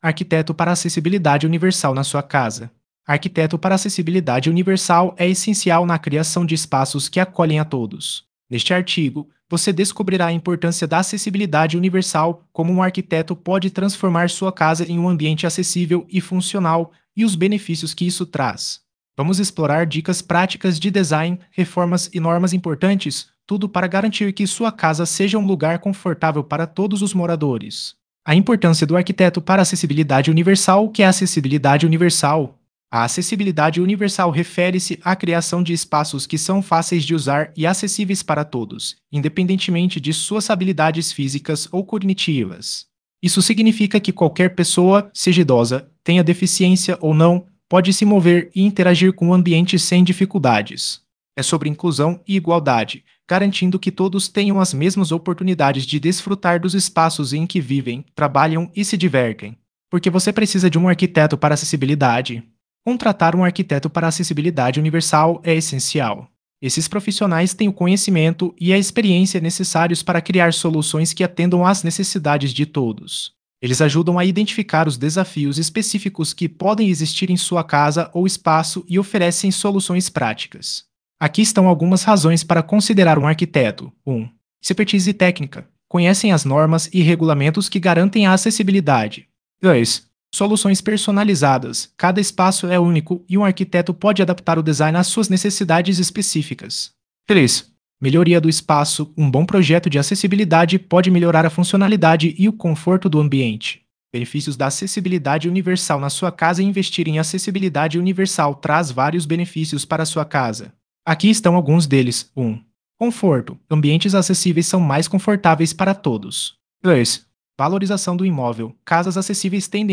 Áudio do artigo